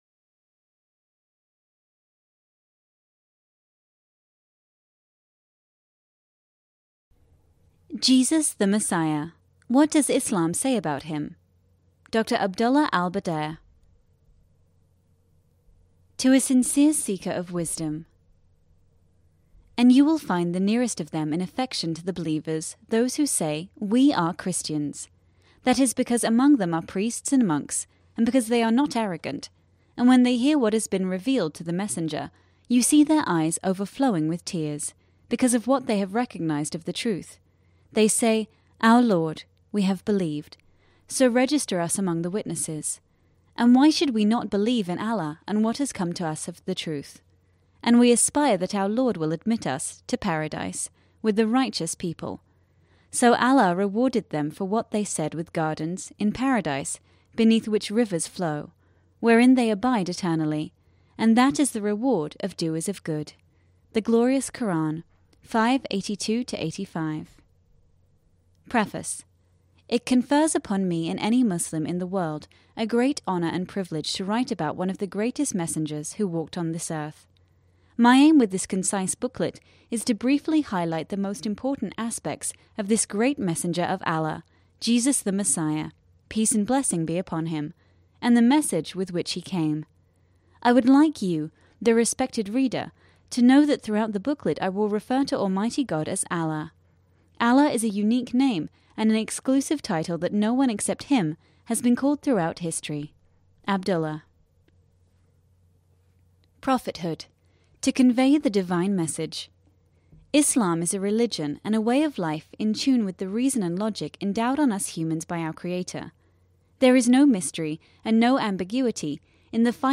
Islamic Audiobooks Central/Jesus the Messiah (Islamic Audiobook